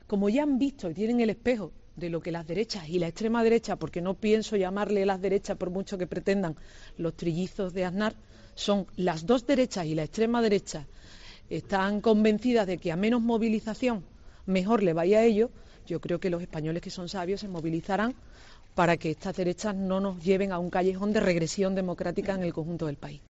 En declaraciones a los periodistas en los pasillos del Parlamento de Andalucía, la expresidenta de la Junta ha dicho que la decisión de Sánchez es "la consecuencia natural" de la posición de "bloqueo" de los grupos independentistas catalanes, así como del PP y de Ciudadanos en el Congreso al rechazar el proyecto de Presupuestos.